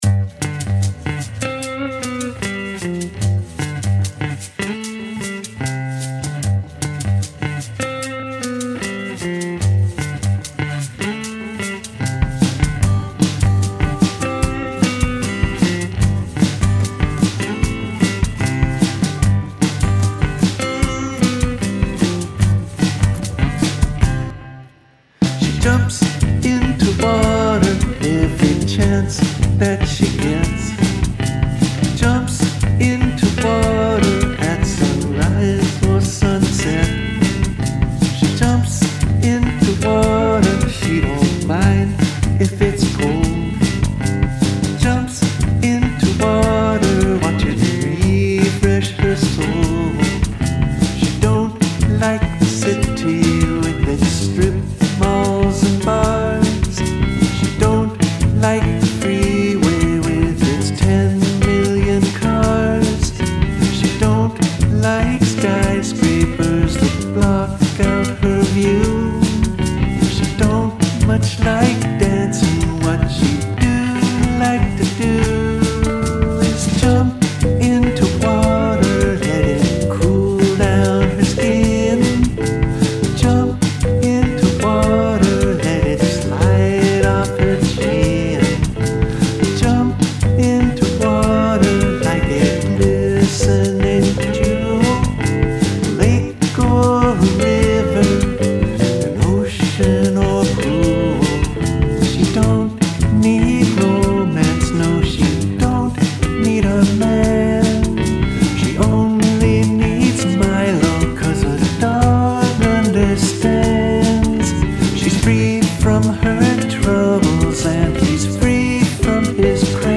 This one just started with a guitar riff. It had a stacatto feel to it, so I tried to choose short, truncated words.